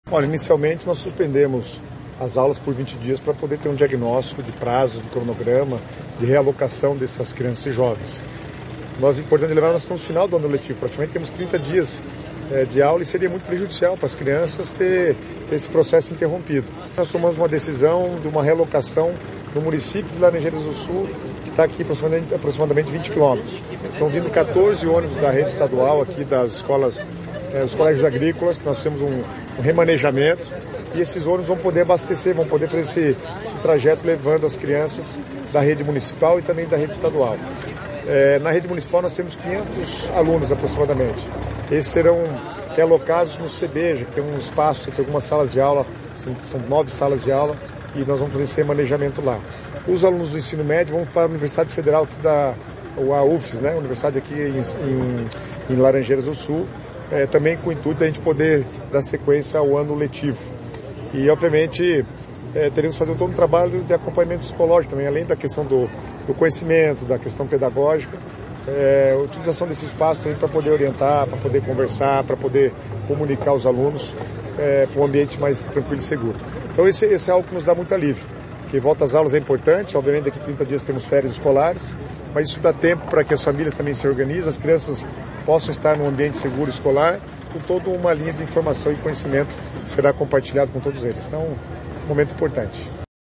Sonora do secretário das Cidades, Guto Silva, sobre a volta às aulas dos alunos de Rio Bonito do Iguaçu